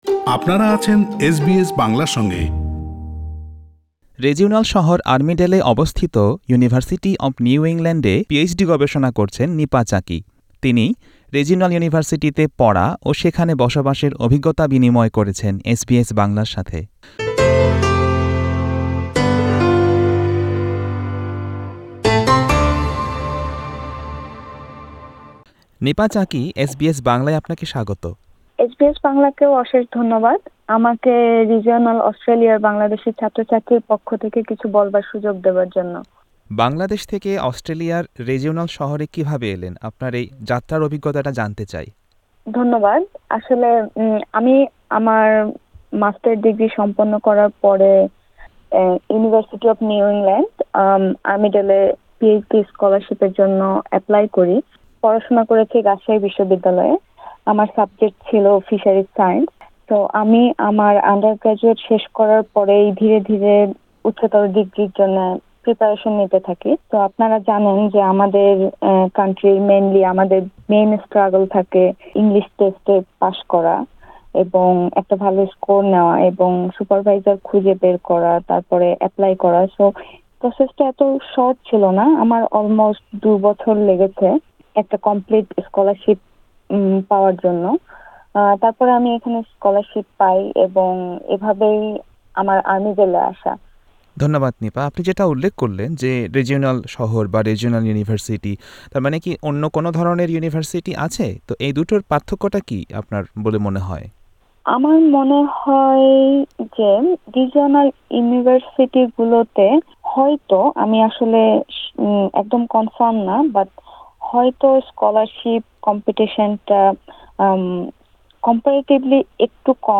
এসবিএস বাংলার সাথে এক আলাপচারিতায় তিনি সেখানে শিক্ষা, গবেষণা ও বসবাসের অভিজ্ঞতা জানিয়েছেন।